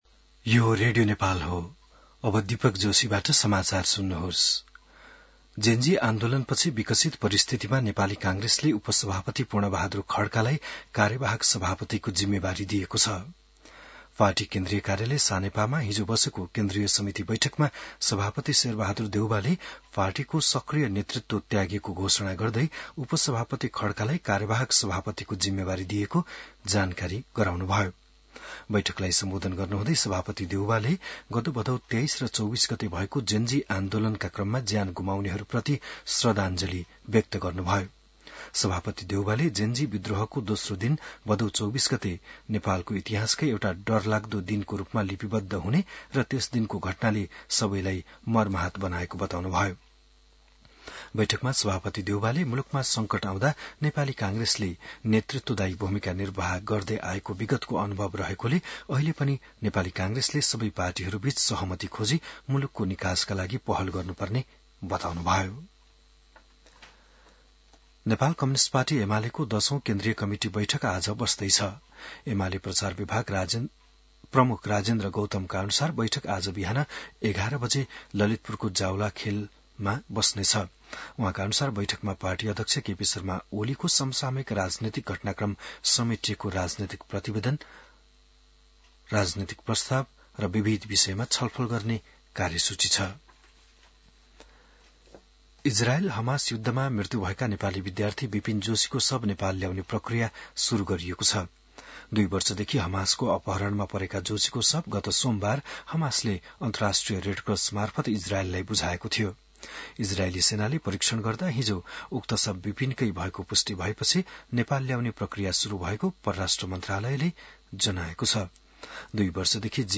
बिहान १० बजेको नेपाली समाचार : २९ असोज , २०८२